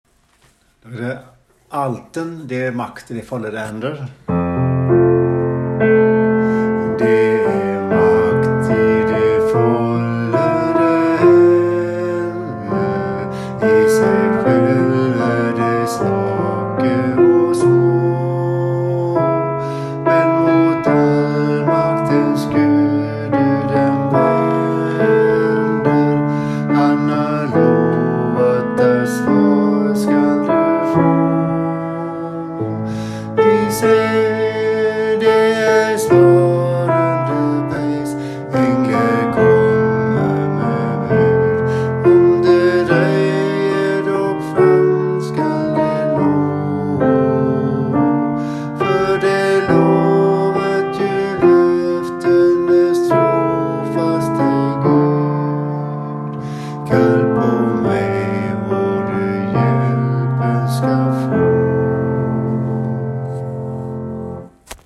Det är makt i de follede hender alt
det er makt_alt.mp3